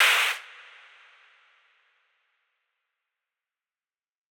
[Clp] Lofi2.wav